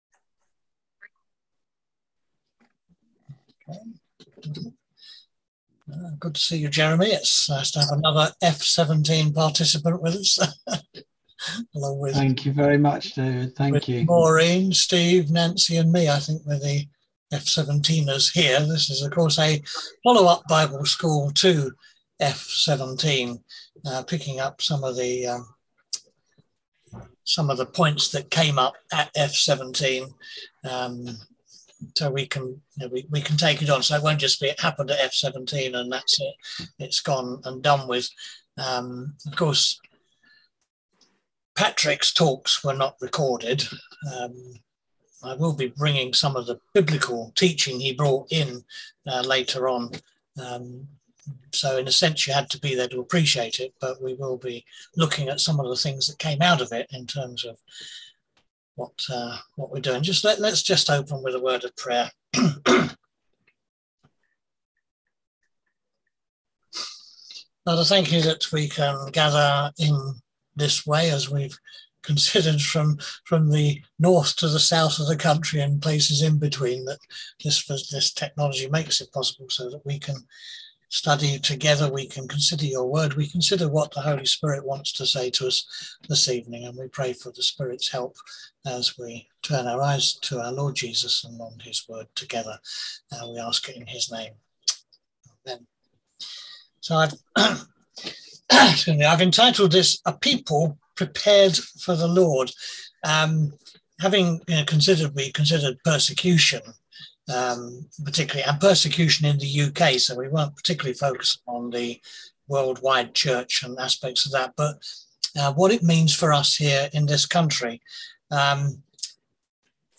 On August 11th at 7pm – 8:30pm on ZOOM